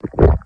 gulpold.ogg